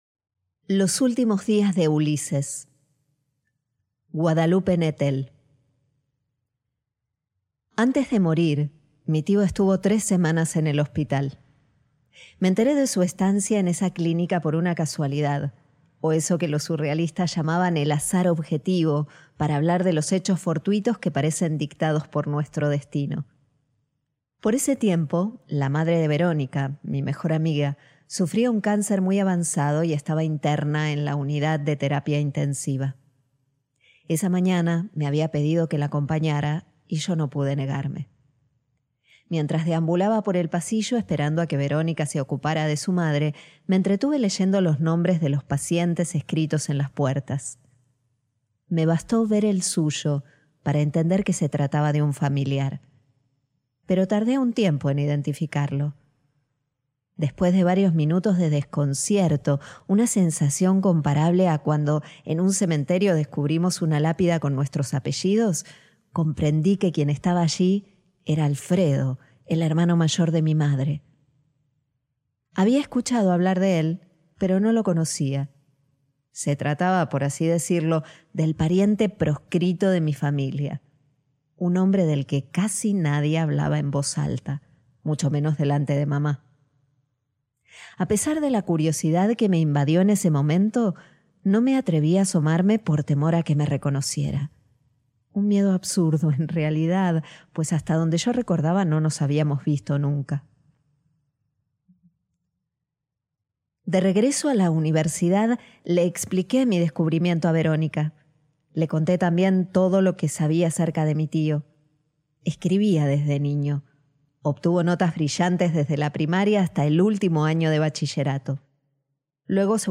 Los últimos días de Ulises Guadalupe Nettel | Audiocuento